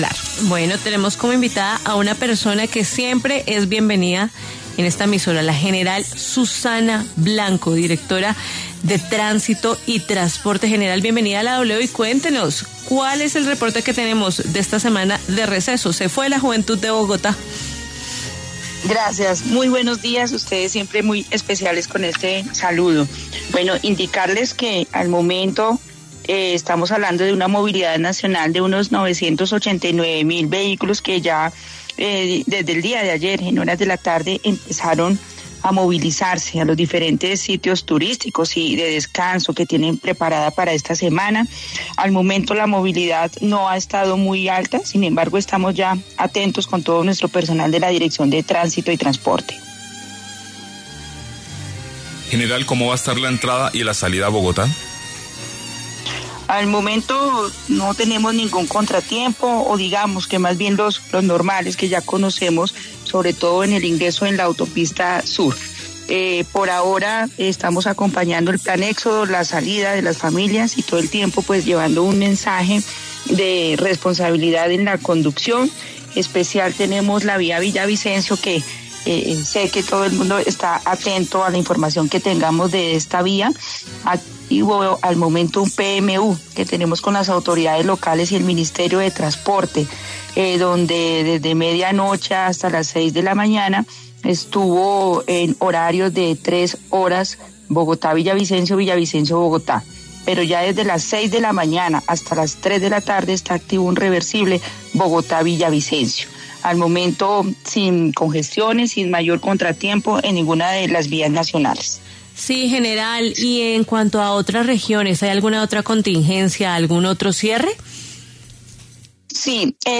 La general Susana Blanco pasó por los micrófonos de W Fin de Semana y entregó un breve balance del ingreso y la salida de las distintas vías principales del país en enlace marco de la semana de receso escolar.